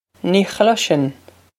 Pronunciation for how to say
Nee cl-ish-in.
This is an approximate phonetic pronunciation of the phrase.